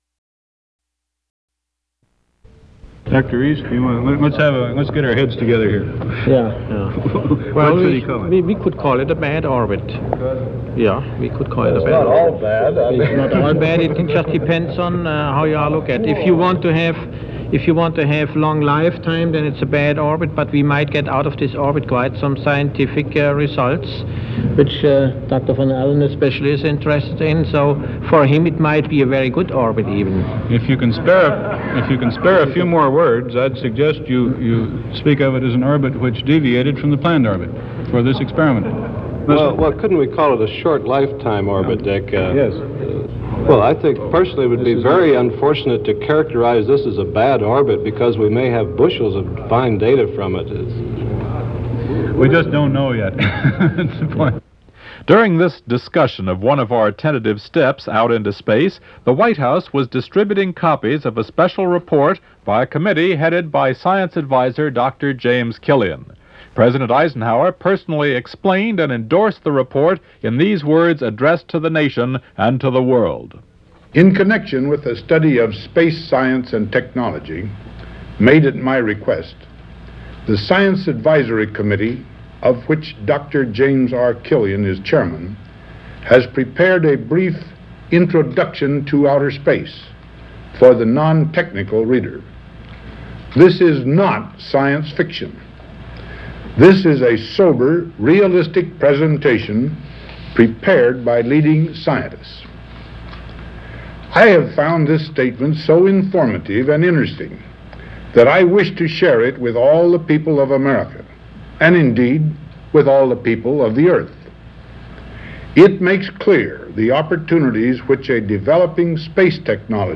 Dwight Eisenhower discusses the imperfect orbit of Explorer 3 and space exploration, with scientists
Broadcast on CBS, March 26, 1958.